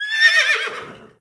c_whorse_atk1.wav